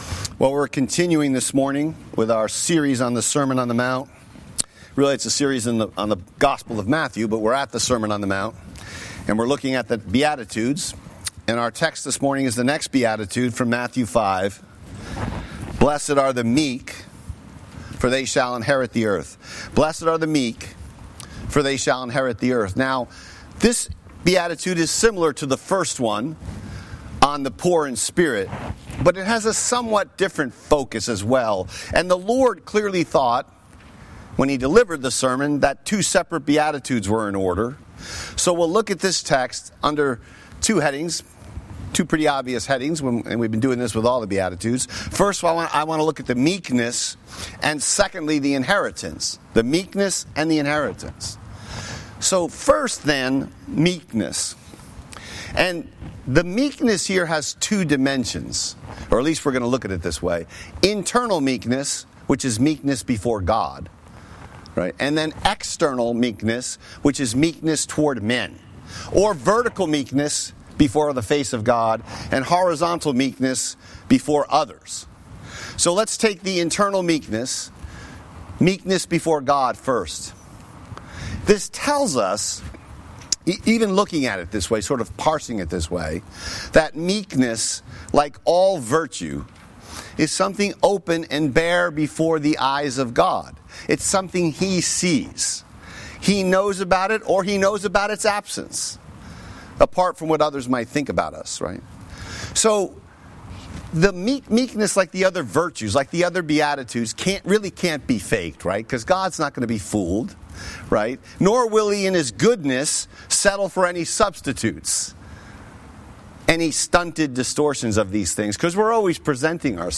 Sermon Text: Matthew 5:1-12